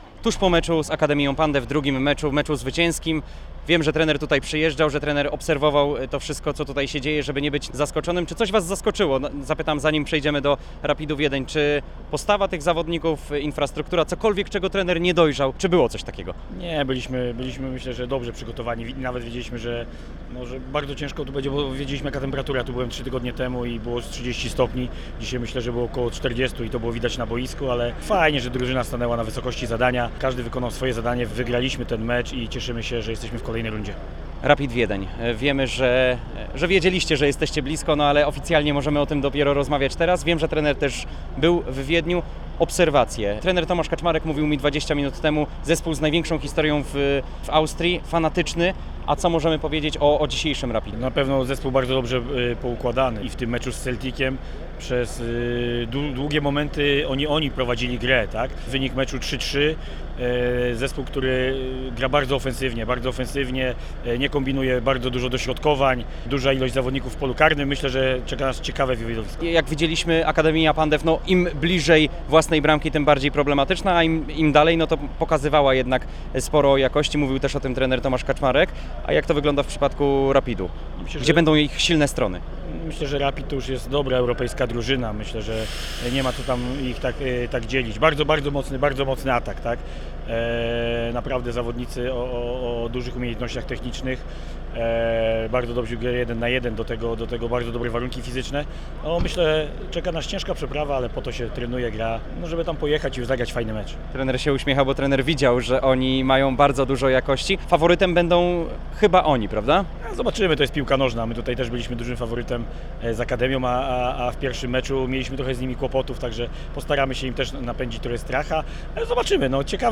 Więcej w rozmowie: